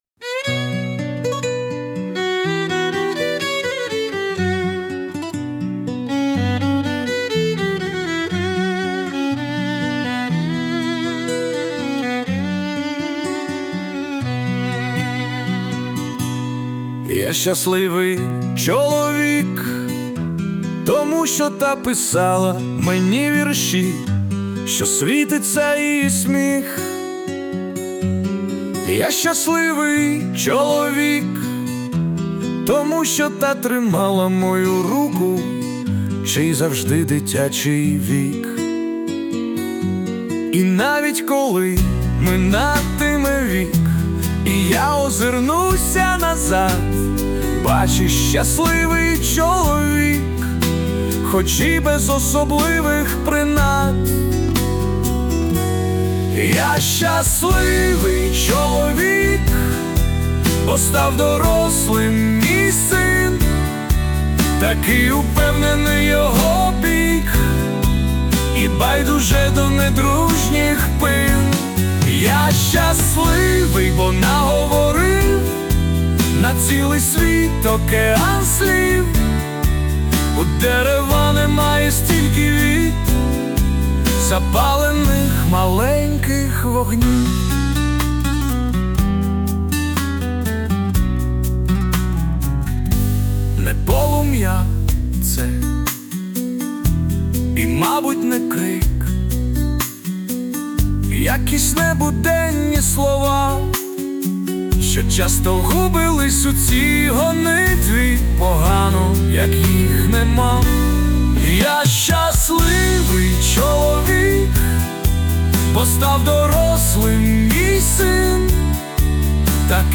Музичне прочитання частини вірша з допомогою ШІ
СТИЛЬОВІ ЖАНРИ: Ліричний